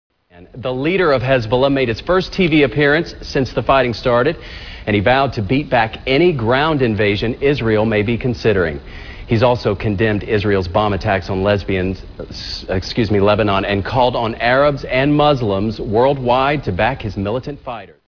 Tags: News Anchor Fail News Anchor Fail clips News fail Reporter fail News fail clips